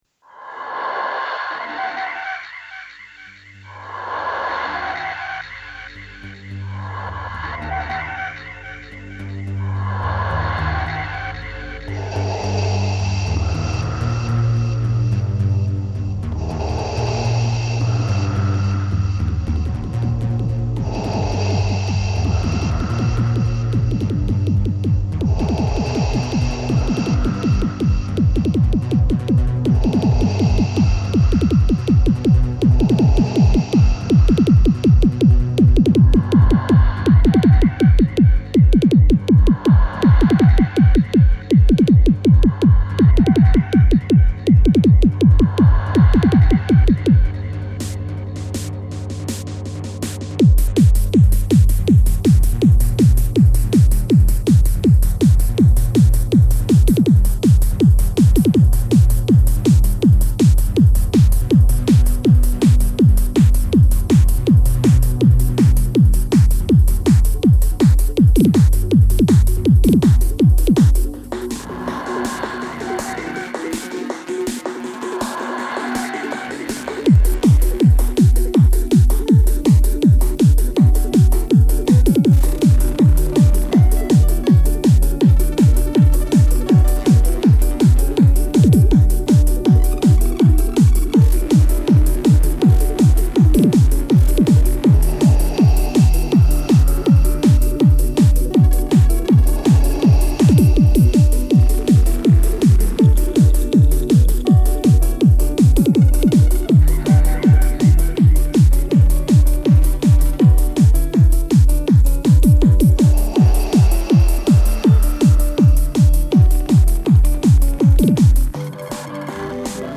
20/10/2014 Etiquetes: techno Descàrregues i reproduccions